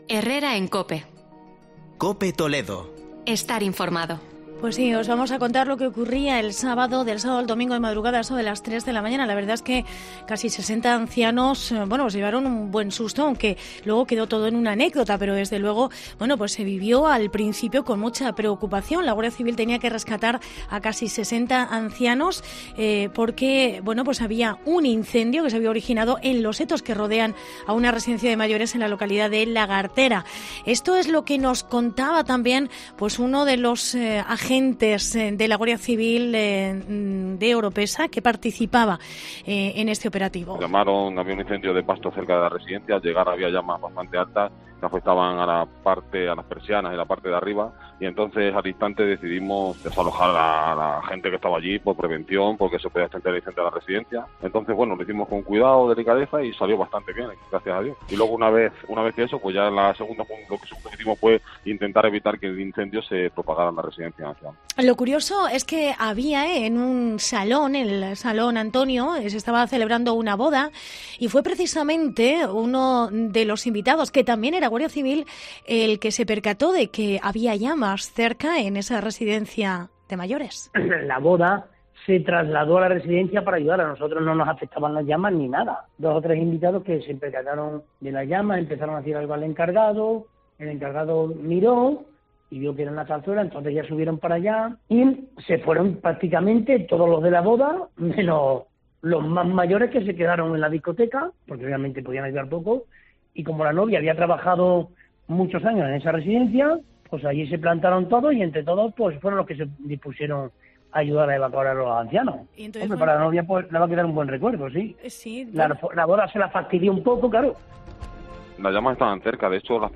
reportaje incendio en Lagartera . Desalojados 60 ancianos de una residencia